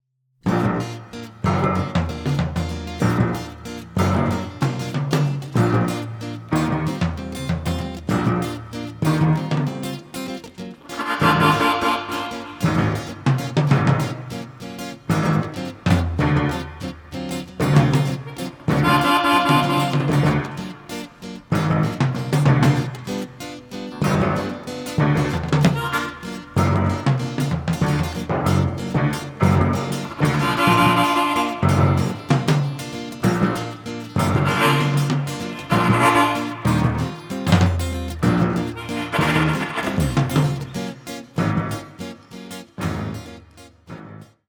trumpeter
cellist
in a luminous and captivating style